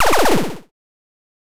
8bit_FX_Shot_02_02.wav